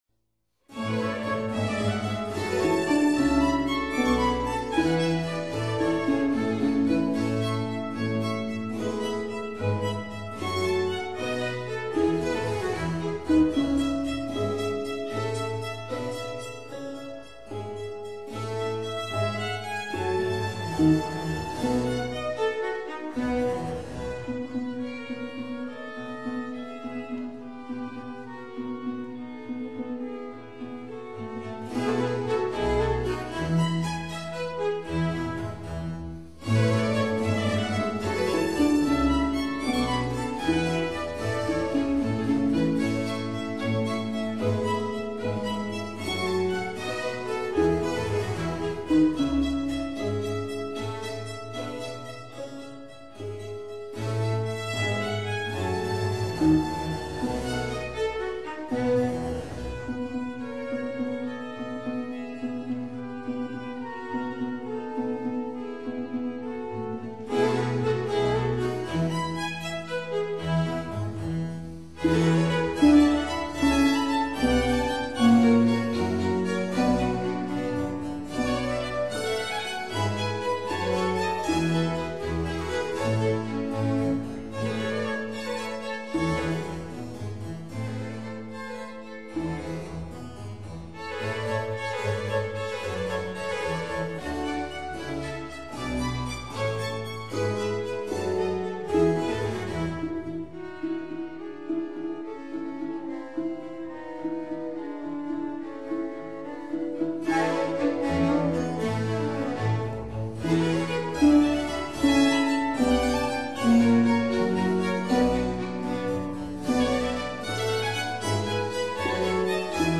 [on period instruments]